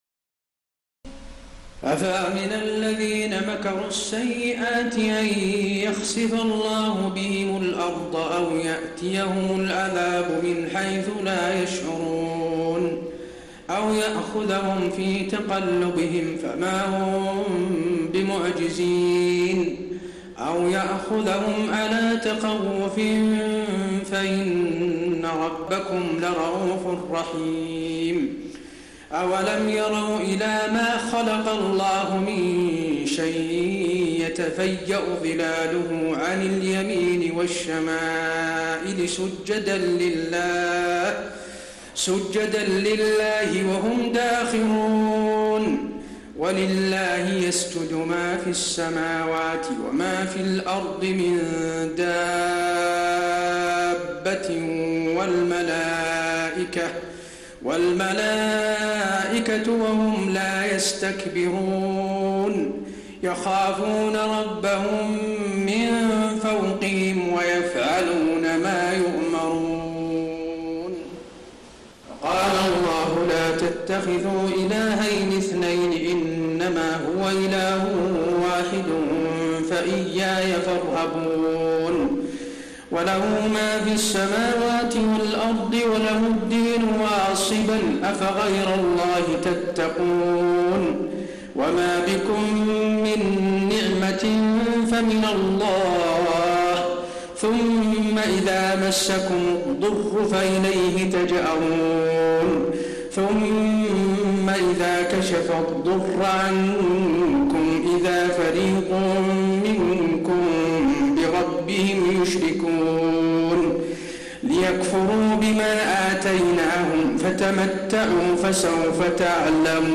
تراويح الليلة الرابعة عشر رمضان 1432هـ من سورة النحل (45-128) Taraweeh 14 st night Ramadan 1432H from Surah An-Nahl > تراويح الحرم النبوي عام 1432 🕌 > التراويح - تلاوات الحرمين